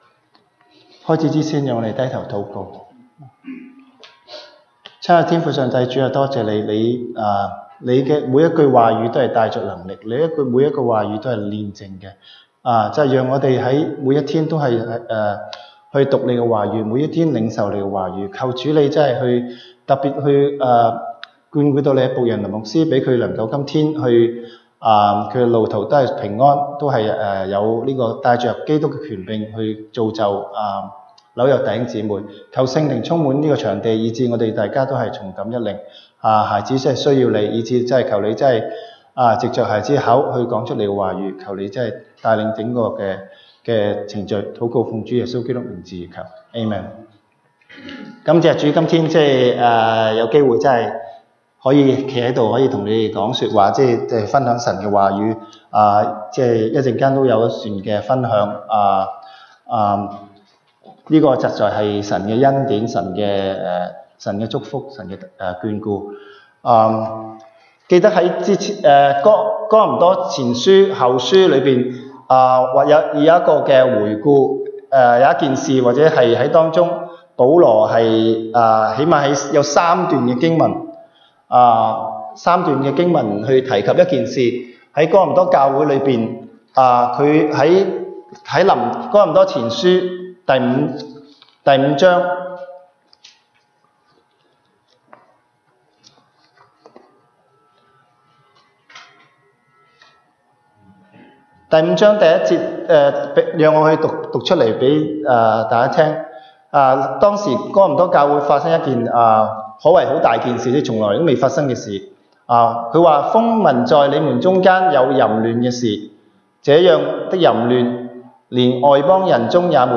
東北堂證道 (粵語) North Side: 你們是看眼前的嗎？